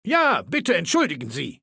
Datei:Maleold01 ms06 goodbye 000681d6.ogg
Bill Seward Kategorie:Fallout 3: Audiodialoge Du kannst diese Datei nicht überschreiben.